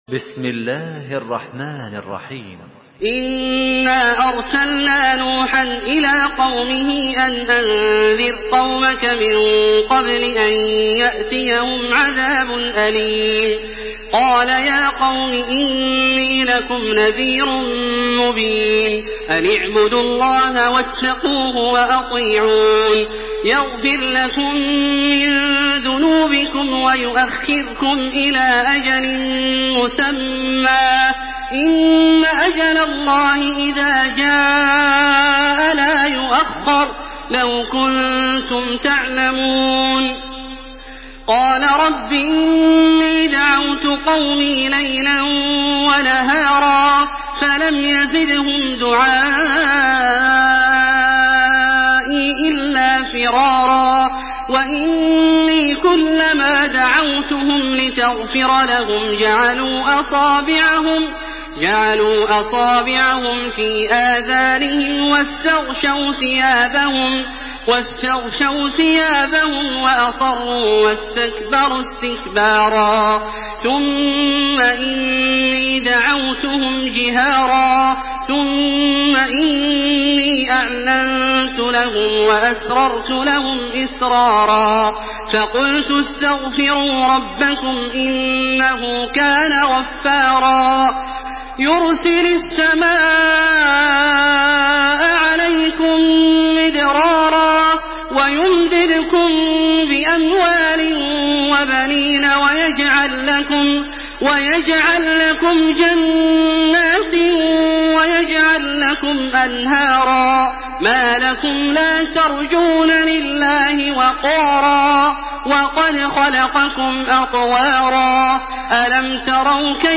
Surah নূহ MP3 by Makkah Taraweeh 1427 in Hafs An Asim narration.
Murattal